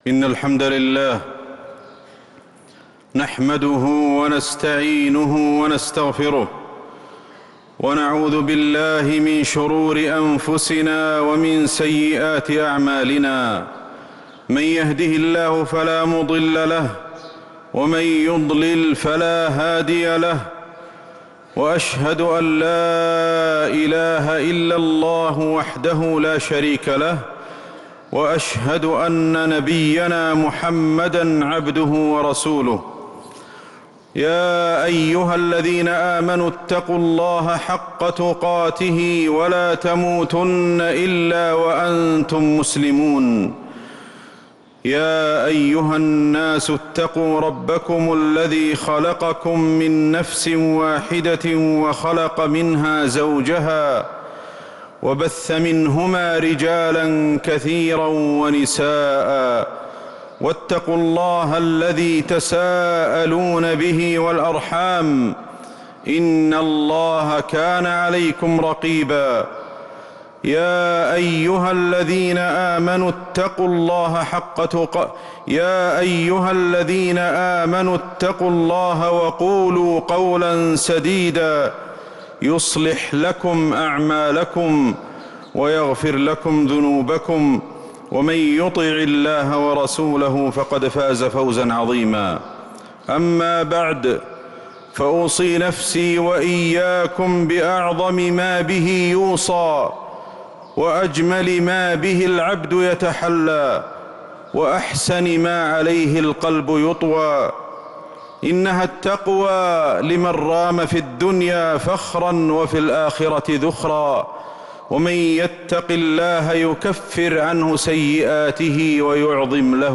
خطبة الجمعة 7-9-1446هـ | Khutbah Jumu’ah 7-3-2025 > الخطب > المزيد - تلاوات الشيخ أحمد الحذيفي
madinahKhutbah.mp3